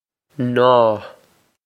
Naw
This is an approximate phonetic pronunciation of the phrase.